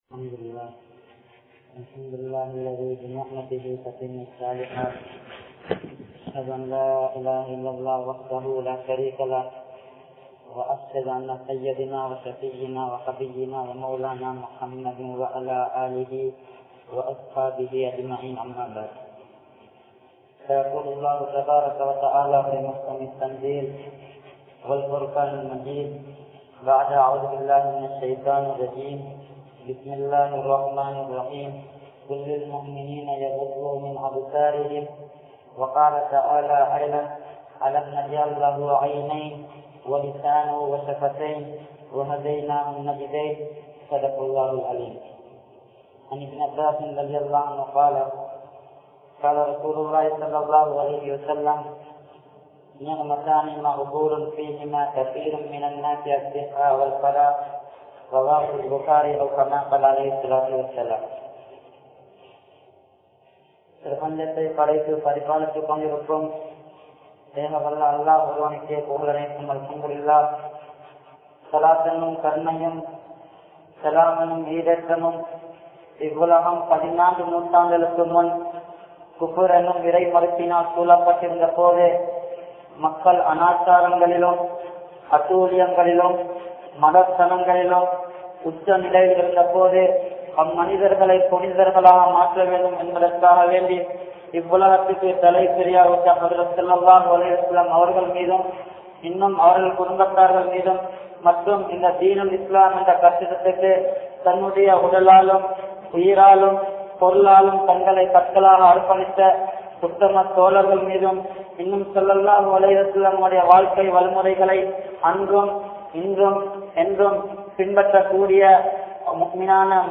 Ketta Paarvaien Vilaivu (கெட்ட பார்வையின் விளைவு) | Audio Bayans | All Ceylon Muslim Youth Community | Addalaichenai
Majmaulkareeb Jumuah Masjith